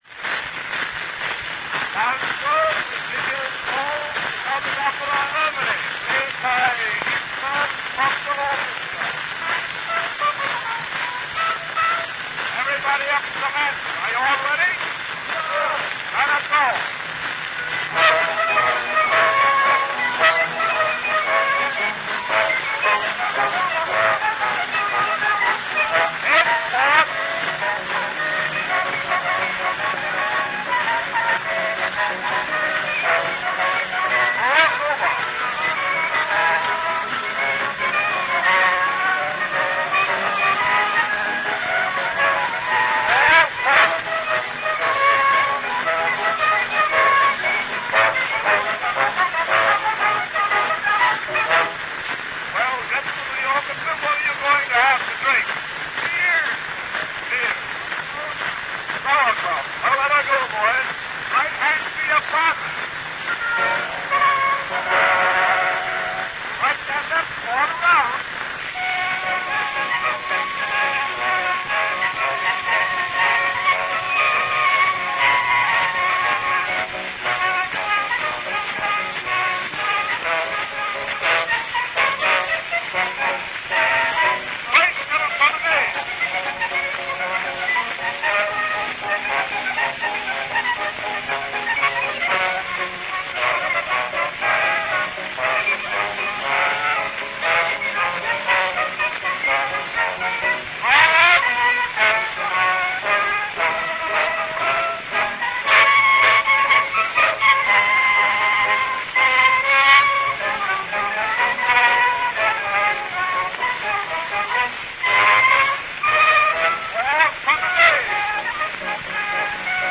RealAudio file from a wax cylinder recording